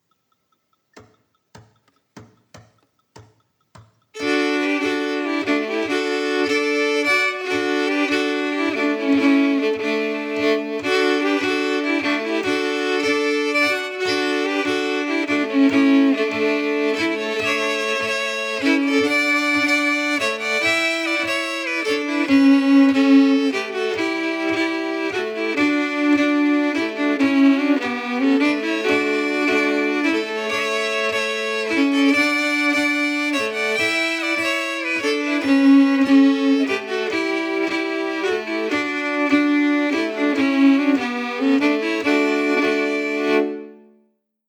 Key: D
Form: Pols (Norwegian polska)
Harmony emphasis
Genre/Style: Norwegian pols (polska)
Fanteladda-harmonies.mp3